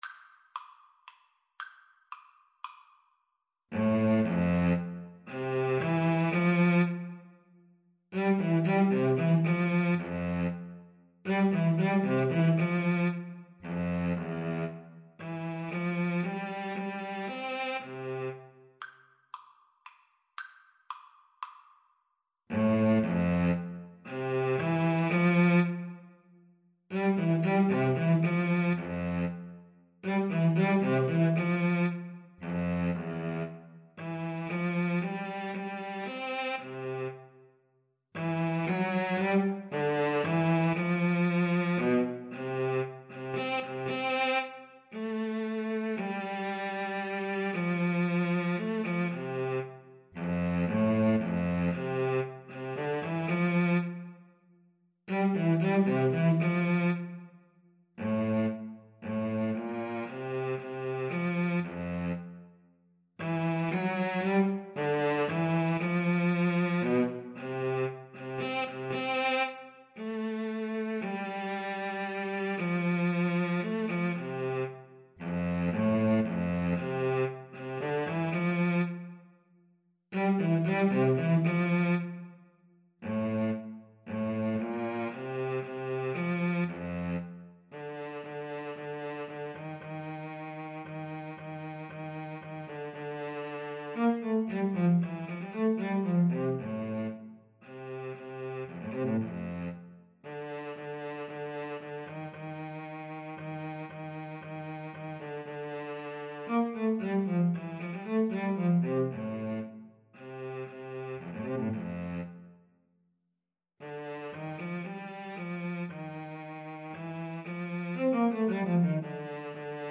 3/4 (View more 3/4 Music)
Classical (View more Classical Clarinet-Cello Duet Music)